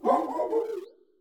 Cri de Toutombe dans Pokémon Écarlate et Violet.